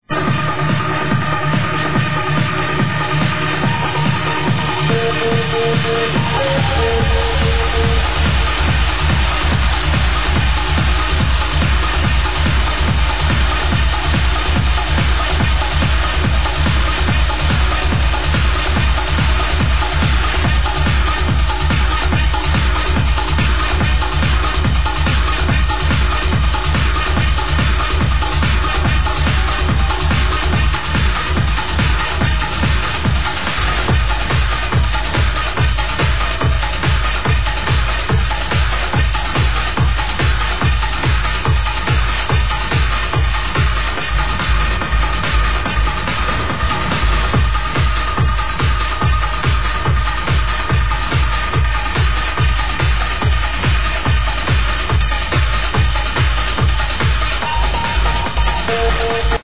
what is this sexy tune?